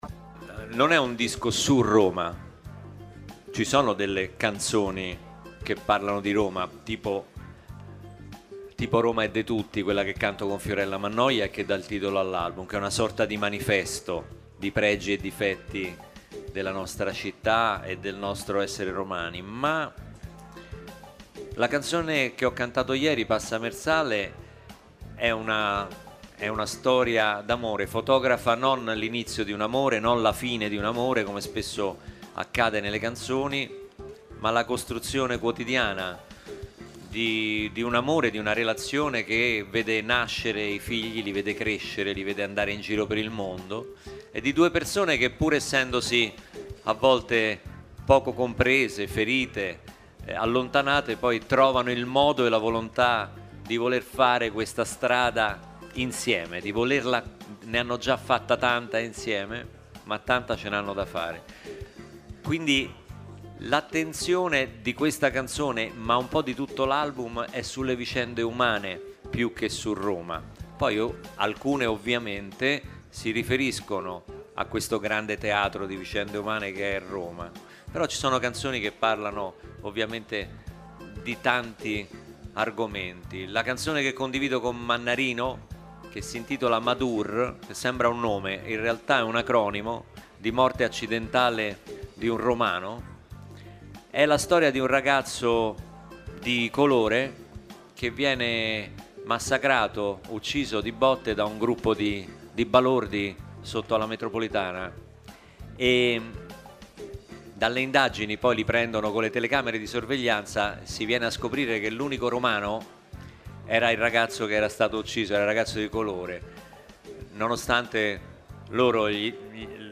In sala stampa arriva Luca Barbarossa.